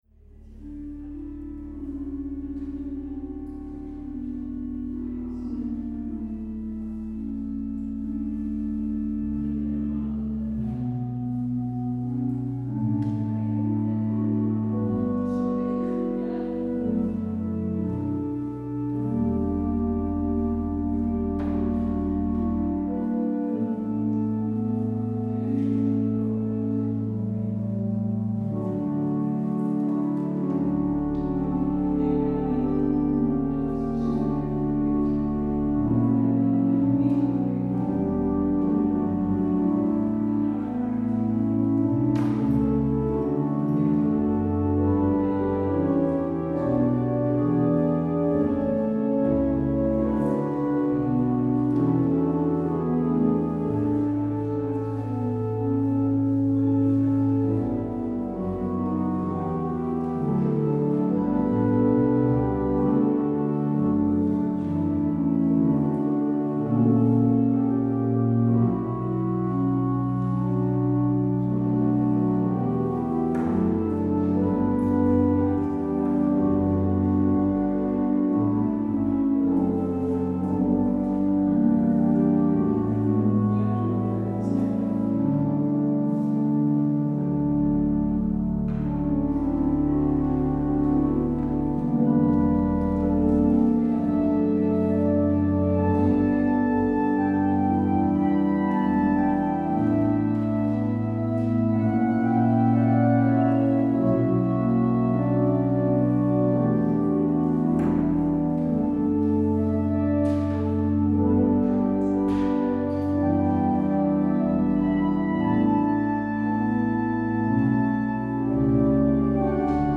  Luister deze kerkdienst hier terug: Alle-Dag-Kerk 30 april 2024 Alle-Dag-Kerk https